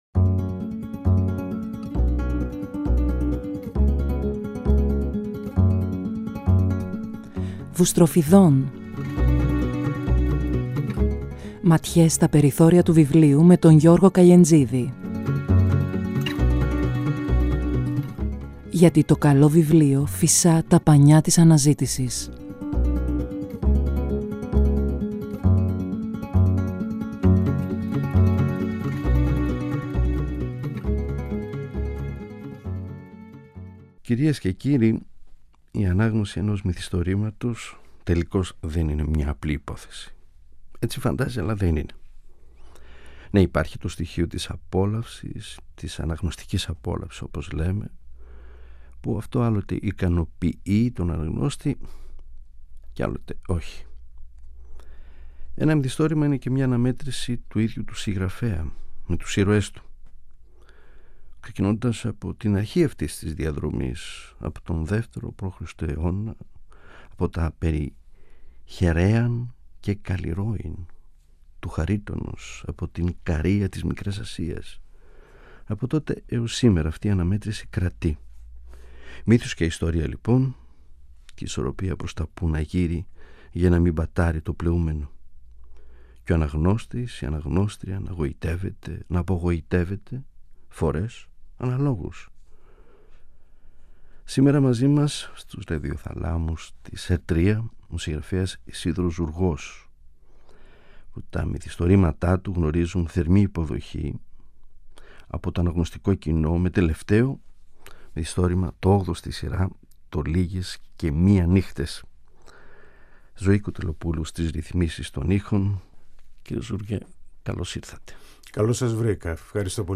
ΠΡΟΣΚΕΚΛΗΜΕΝΟΣ: Ισίδωρος Ζουργός – Πεζογράφος